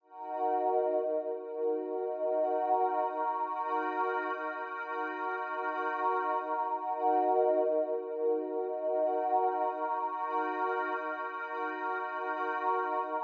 描述：陷阱和科幻的结合。沉重的打击和神秘感。使用这些循环来制作一个超出这个世界范围的爆炸性节目吧 :)D小调
Tag: 145 bpm Trap Loops Pad Loops 2.23 MB wav Key : D